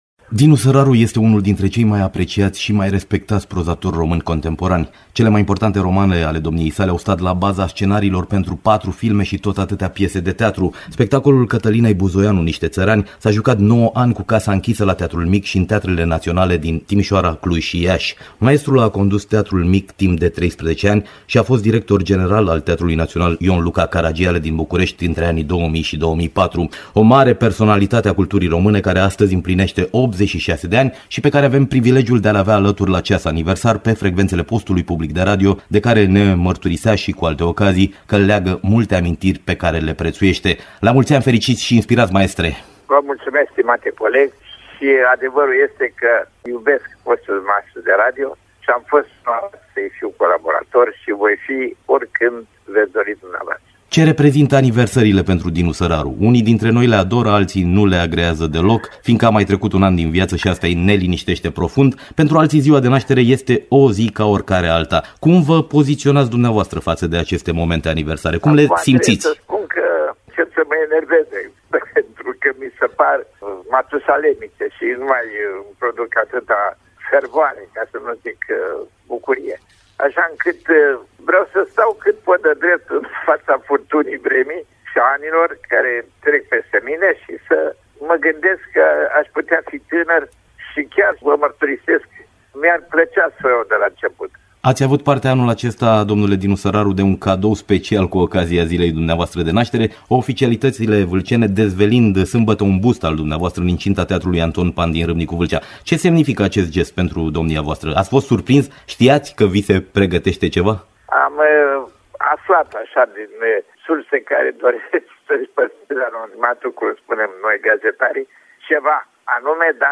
La aniversară, celebrul scriitor a făcut mărturisiri și predicții la Radio Oltenia.